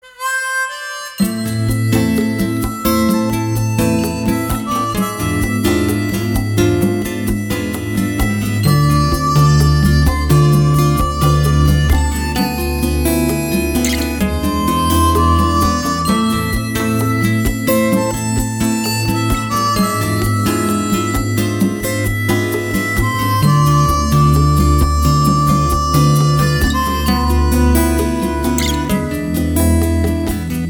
• Качество: 192, Stereo
инструментальные
рок
спокойная мелодия